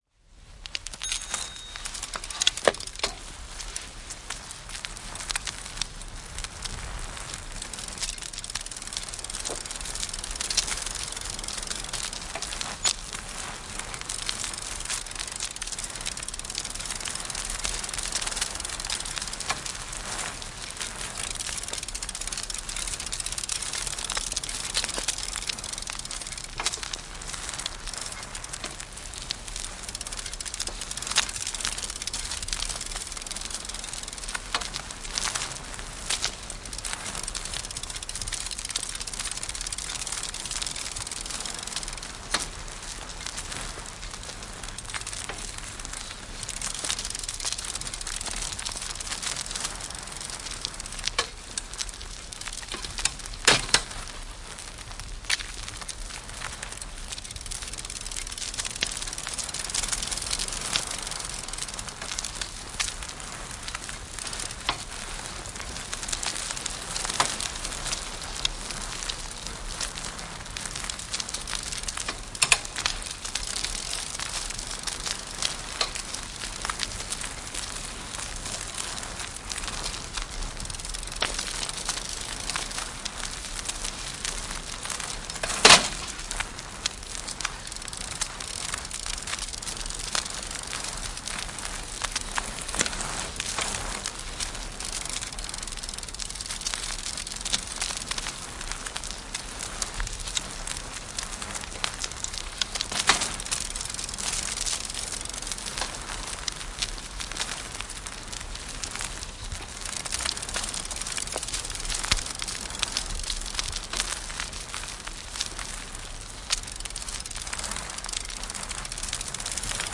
Ihminen Human » ATM (automated teller machine) // Pankkiautomaatti
描述：Paying a bill with barcode using ATM. Beeping, printing receipt. Lasku maksetaan viivakoodilla pankkiautomaatilla, piipityksiä, kuitin tulostus, laitteen loksahduksia, lähiääni.
标签： loksahduksia maksaminen kuitintulostus pankkiautomaatti printingreceipt automatedtellermachine beeping ATM piipitys finnishbroadcastingcompany fieldrecording soundfx yleisradio paying
声道立体声